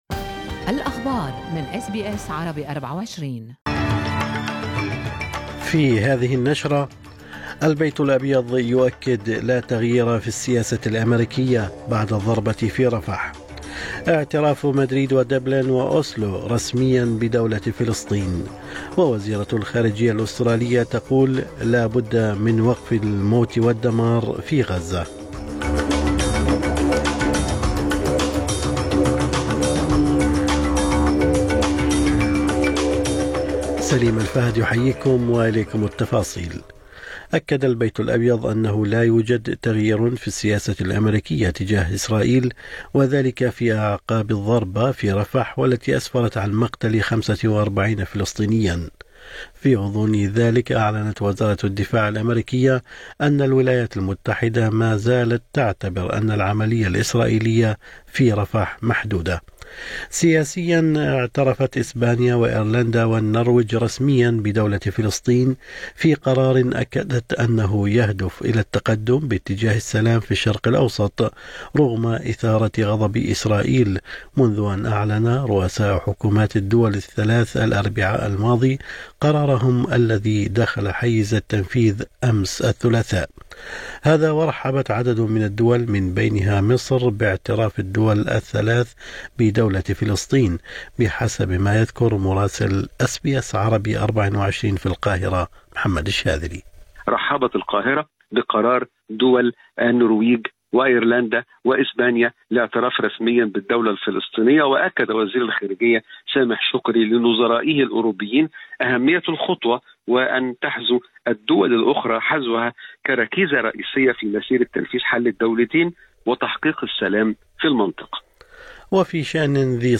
نشرة أخبار الصباح 29/5/2024